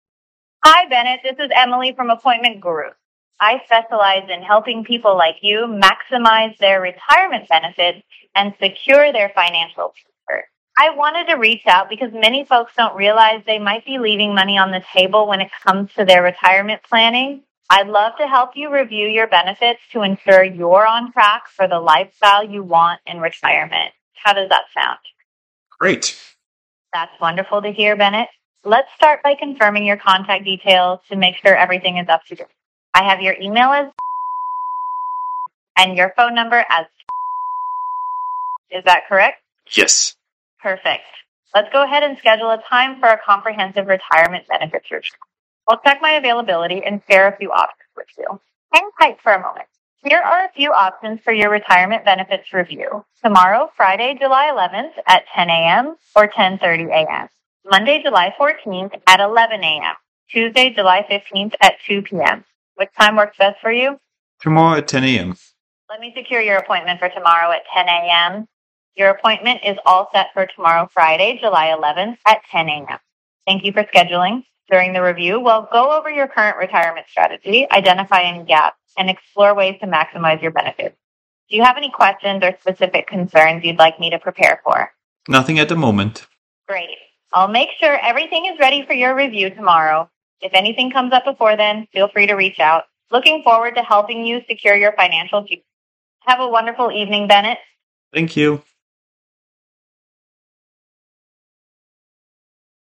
Our intelligent AI voice agents initiate real-time phone conversations with your prospects, qualify interest, and route qualified leads directly to your sales team.
Conversational AI
Our AI Calling Services use advanced voice technology to simulate natural phone conversations with prospects.
• Speak clearly and conversationally.
Let Our AI Do the Talking!